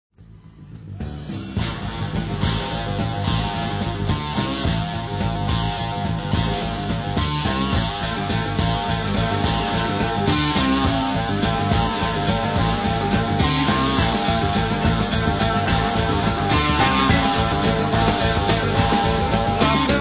Southern (jižanský) rock